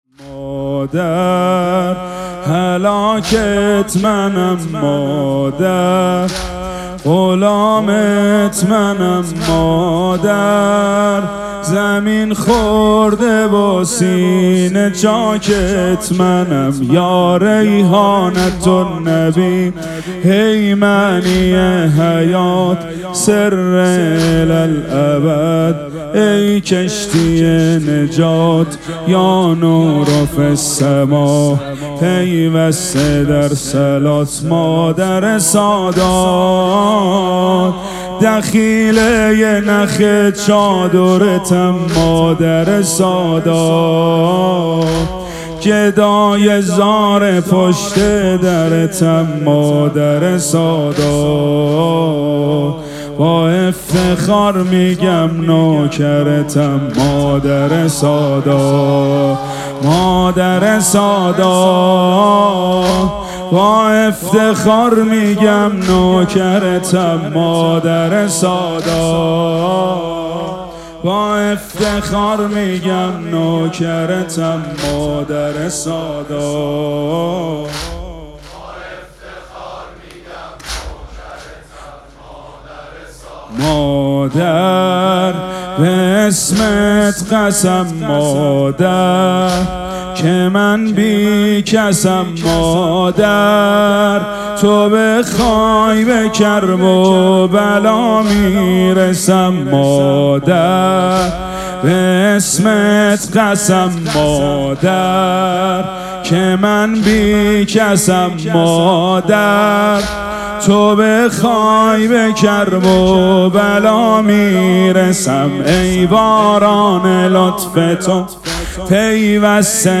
شب دوم مراسم عزاداری دهه دوم فاطمیه ۱۴۴۶
حسینیه ریحانه الحسین سلام الله علیها